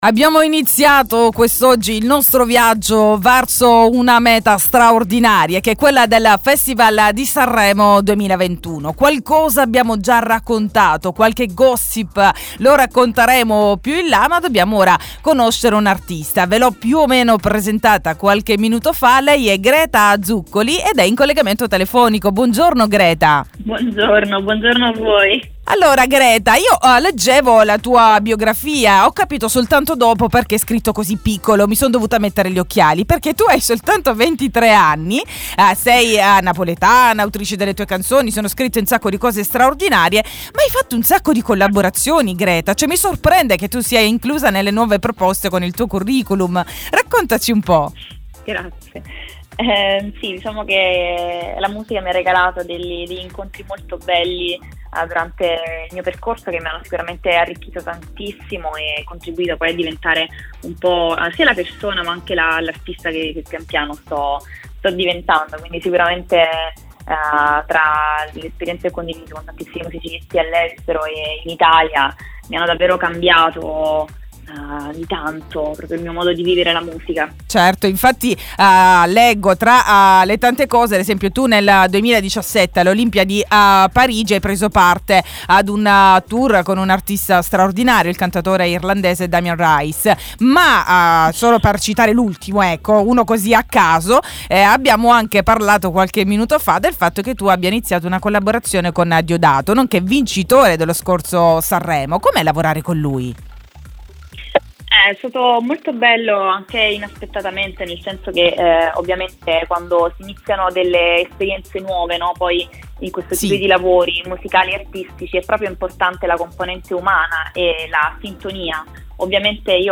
La sua storia, le sue aspettative raccontate in diretta.